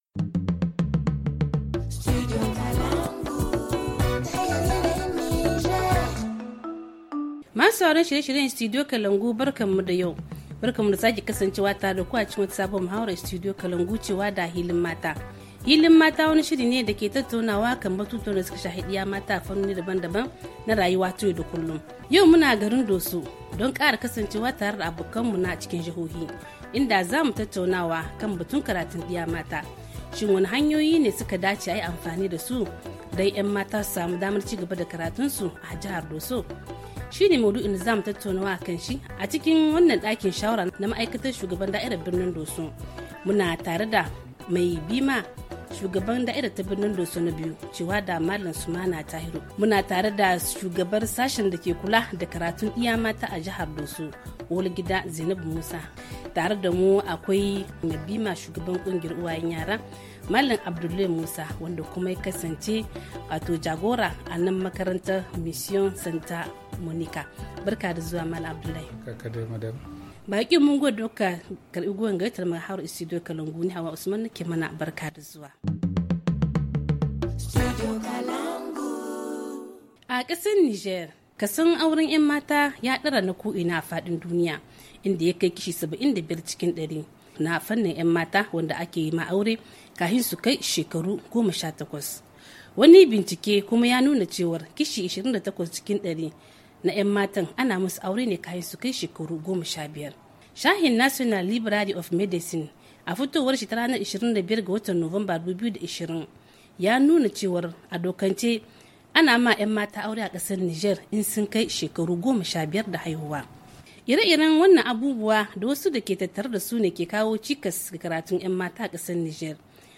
HA Le forum en haoussa https